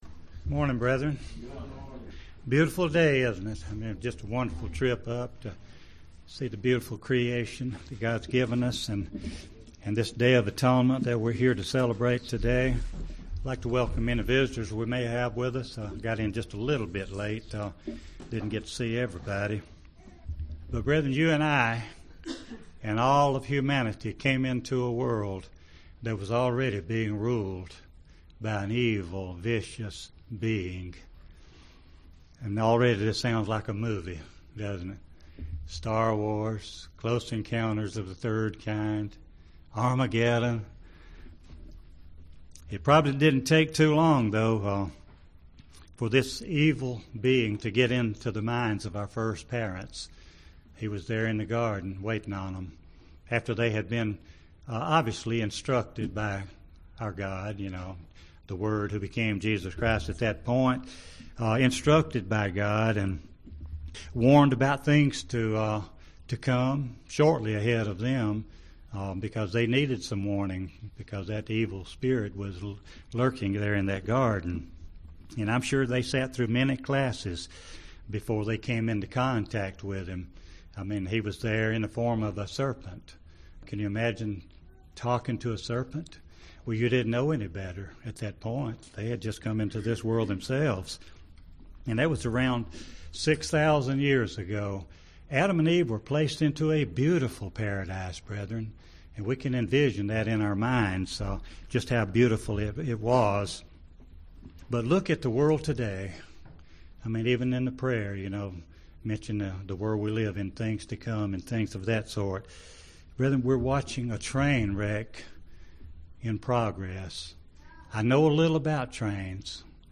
Sermons
Given in Gadsden, AL